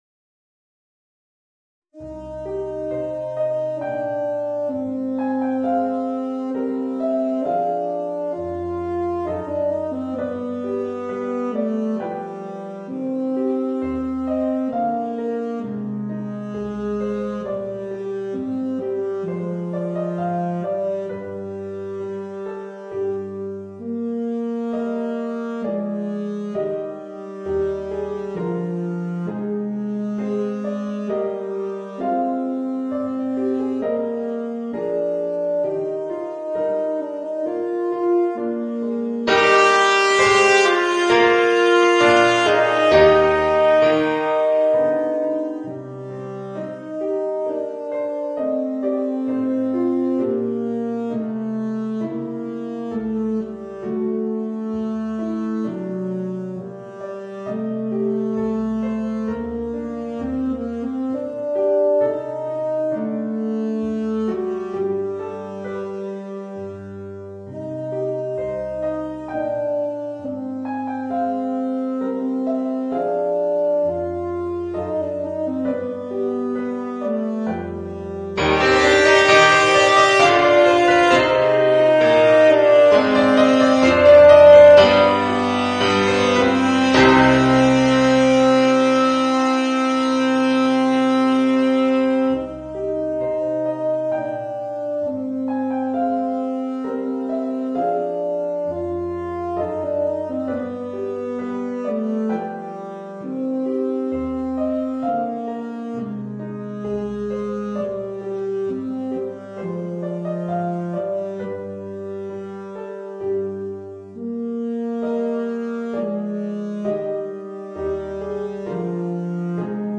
Voicing: Tenor Saxophone and Organ